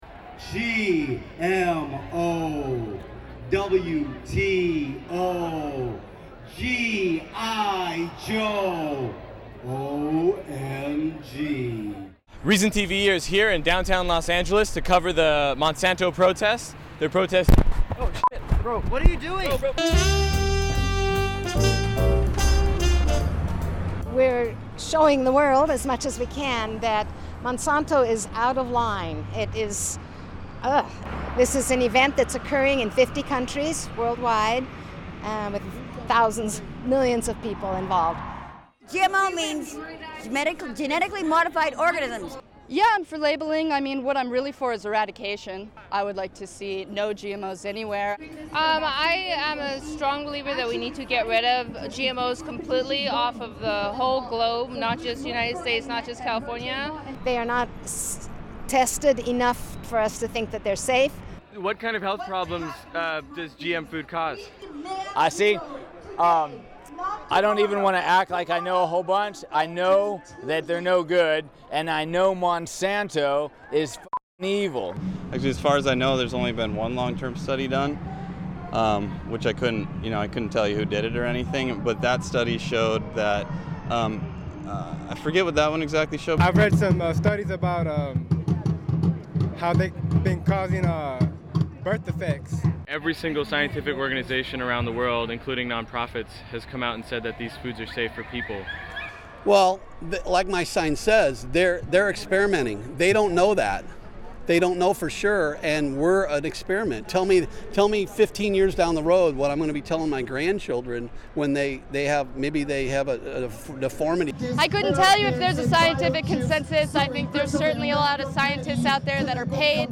Reason TV visited the "March Against Monsanto" rally to find out what the protesters hoped to accomplish and why they continued to reject the prevailing pro-GMO science.